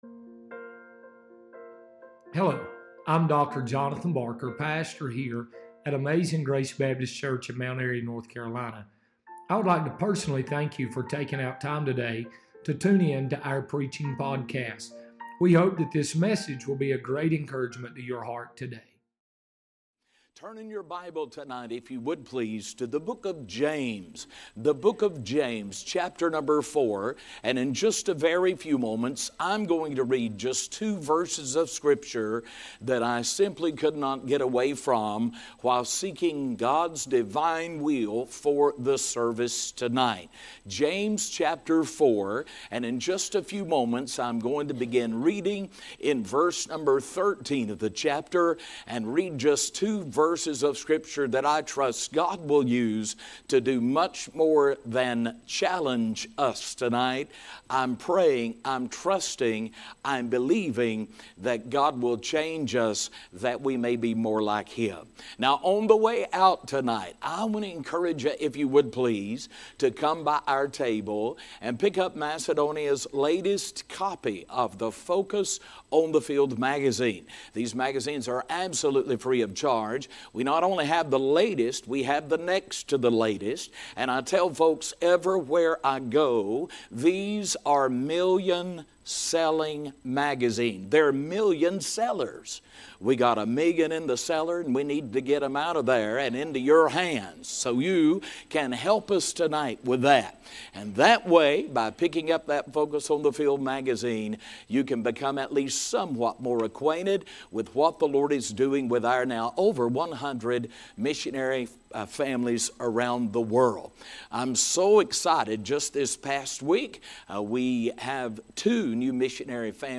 Sermons | Amazing Grace Baptist Church